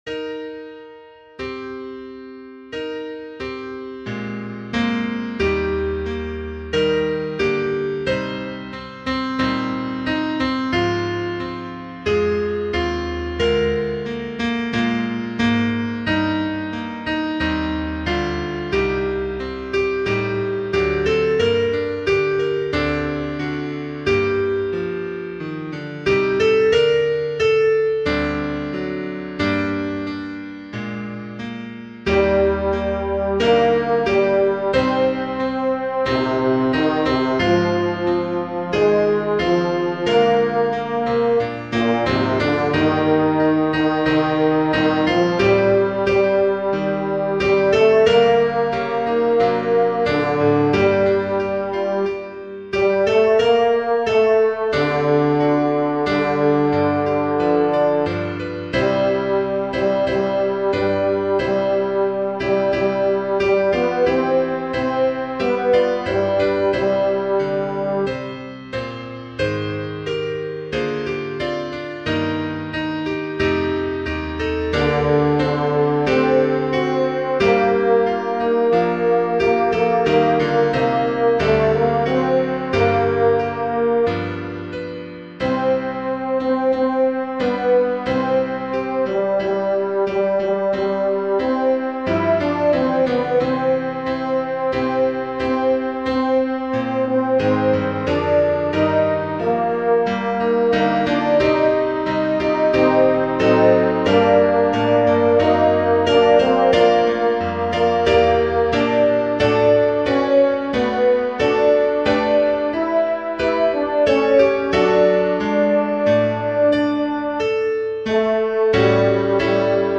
o-sacrum-convivium2-tenor.mp3